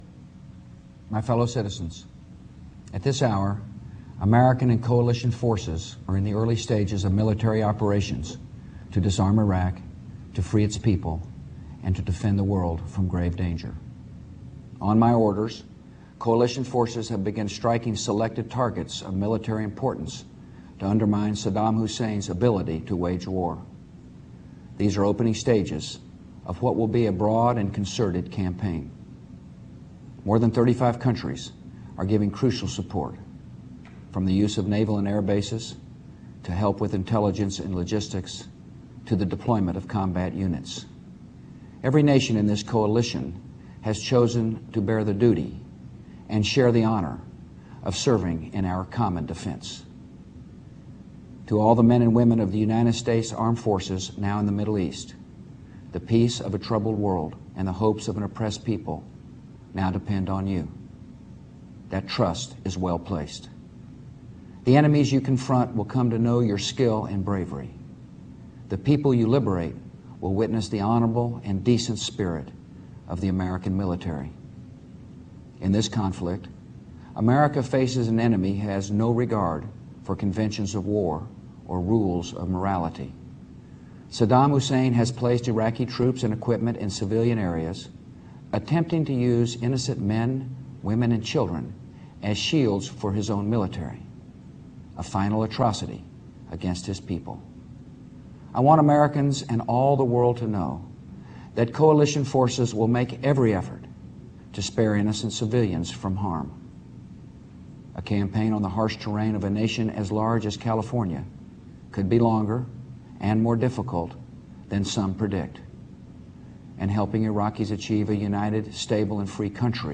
President George W. Bush's addresses the nation from the Oval Office announcing that the first attacks against Iraq have begun. Bush says U.S. goals in "Operation Iraqi Freedom" are to disarm Iraq of weapons of mass destruction, free it's people and to maintain world peace.